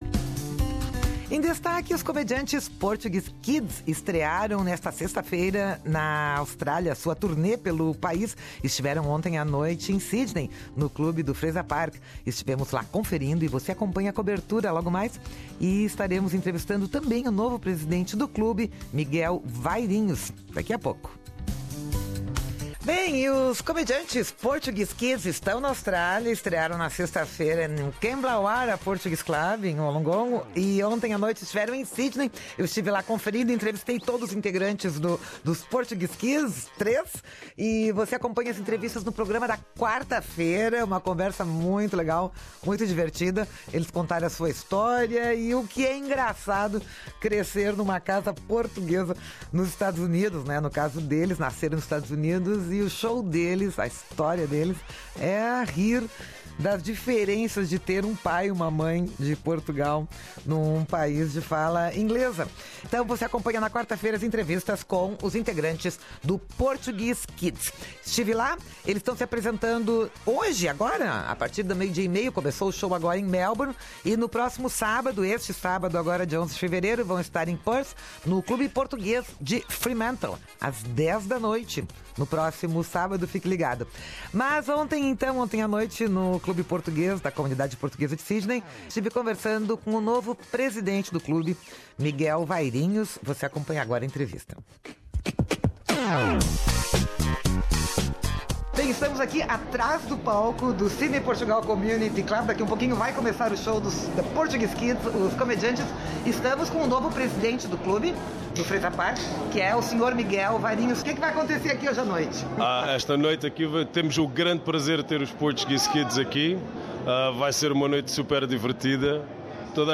Estivemos lá conferindo e você acompanha a entrevista com o novo presidente do clube